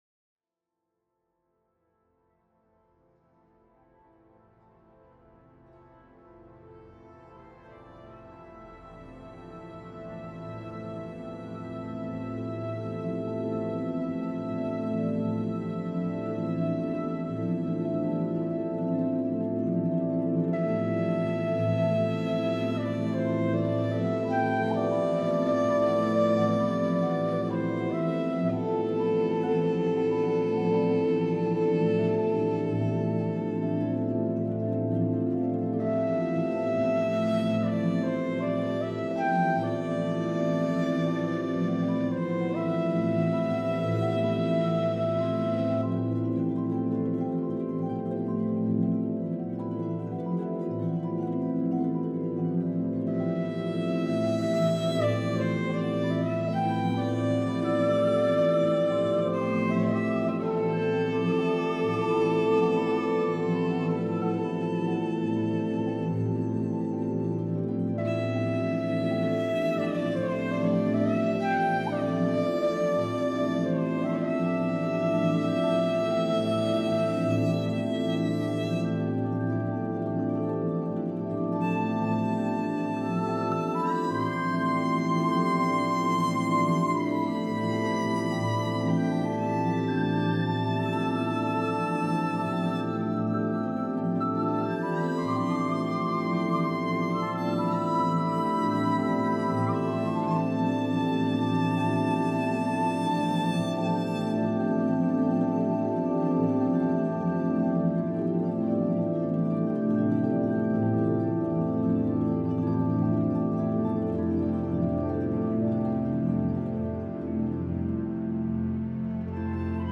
Listen to them through headphones, if possible, with your eyes closed and with a generous amount of gain, as the dynamic rage is pretty open, pretty wide.
You will see that there is a melody in the first one, which suggests what has been lost, or the beauty of what has been lost, then this melody is retaken in many, many ways, through the four movements, it comes back in the mood of the situation.